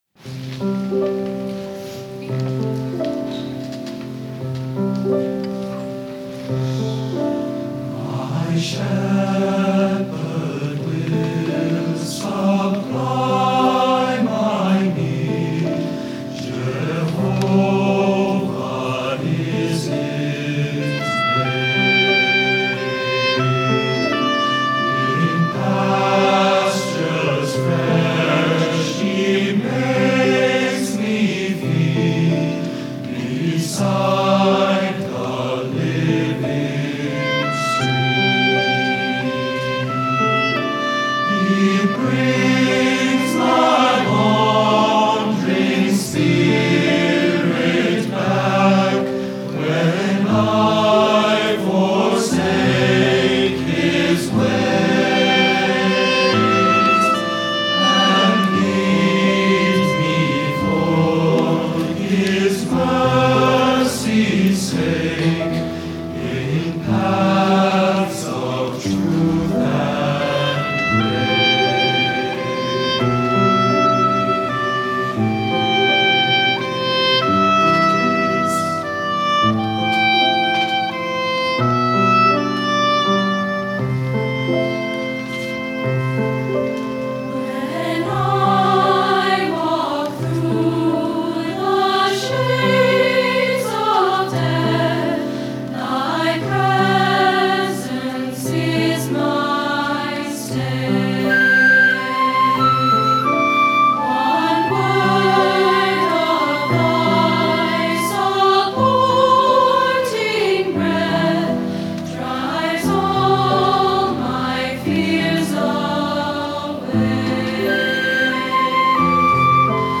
Spring Music Festival